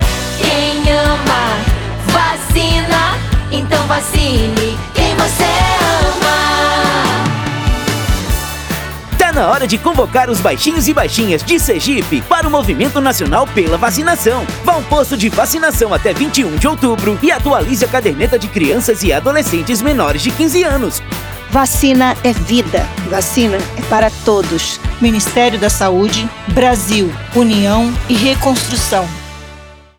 Áudio - Spot 30seg - Campanha de Multivacinação em Sergipe - 1,1mb .mp3